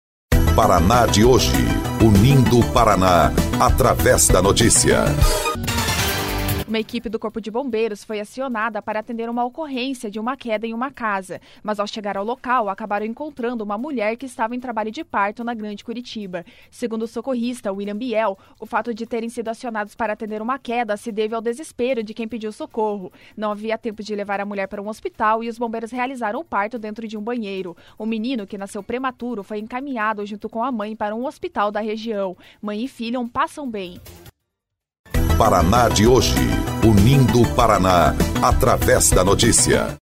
08.01 – BOLETIM – Bombeiros realizam um parto dentro de banheiro, em Curitiba